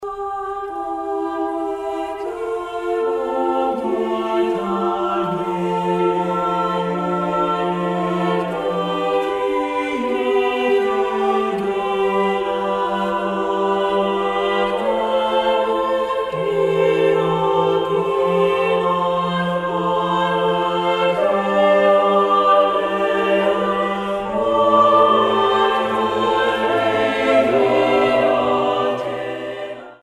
soprano
• Studio : Église Saint-Matthias